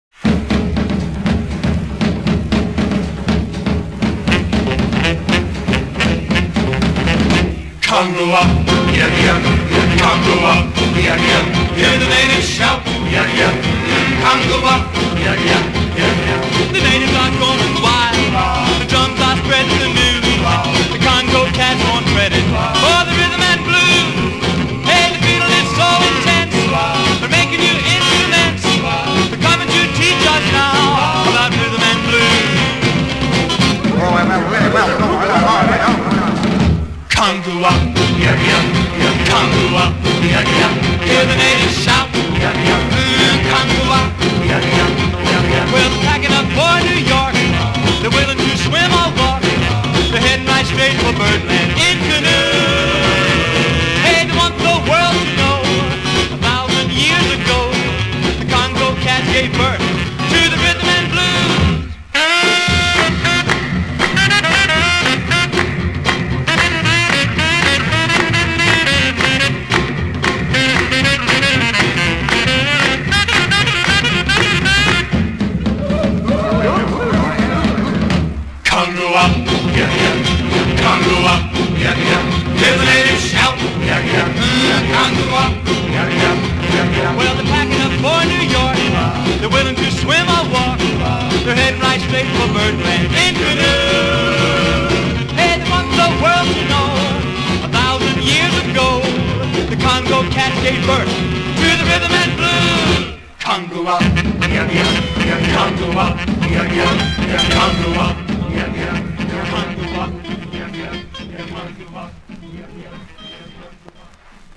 Моно